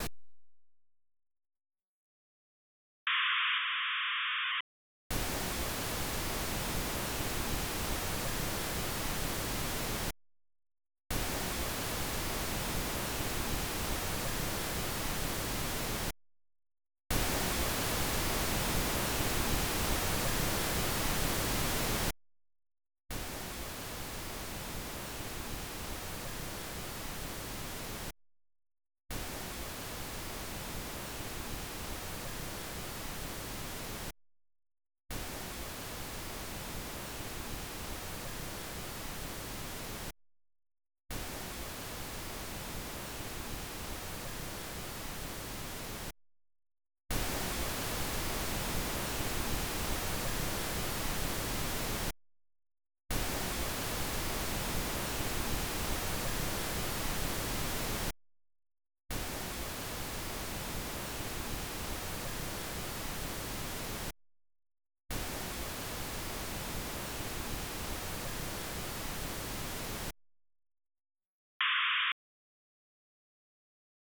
Sequence Pink Noise 7.1.4 file:
Sequence Pink Noise 7.1.4.m4a